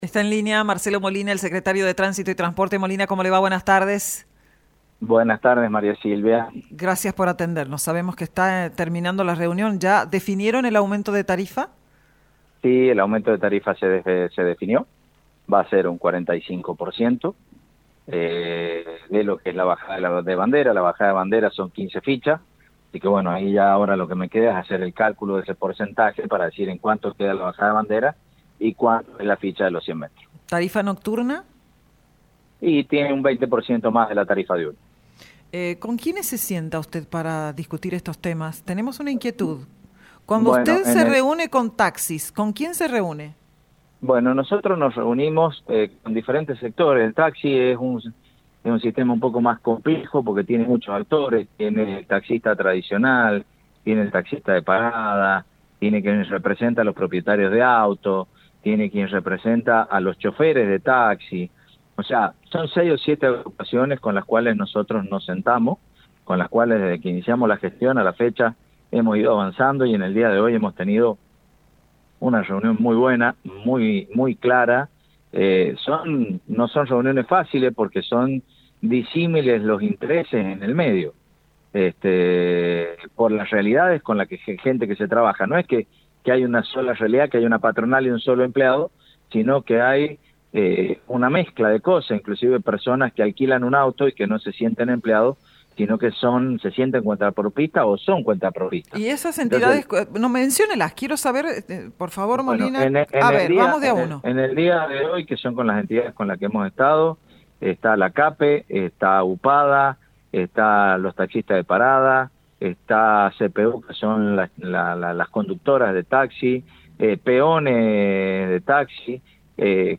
Así lo definió Marcelo Molina, Secretario de Tránsito y Transporte de la provincia, quien en dialogo con ESTACION CLARIDAD, conceptualizó pormenores de las reuniones mantenidas con representantes del sector para llegar a un acuerdo.